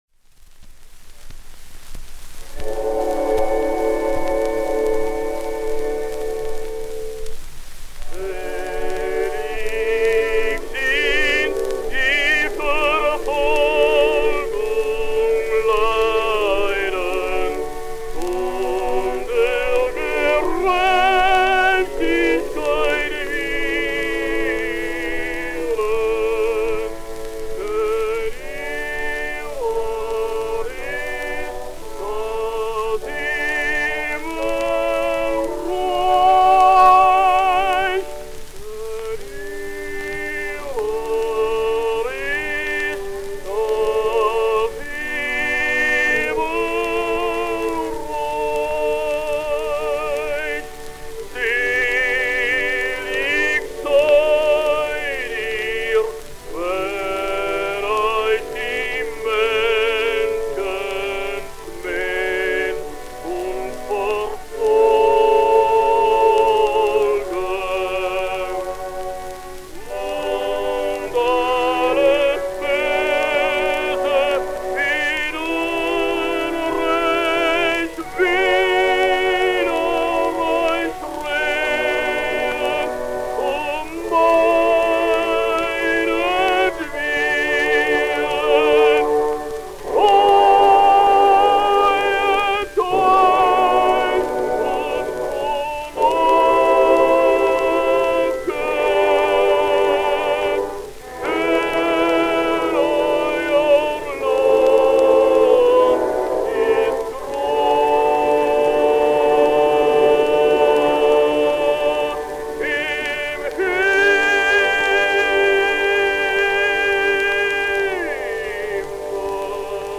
Willi Birrenkoven singsDer Evangelimann:
Gramophone, Hamburg 1907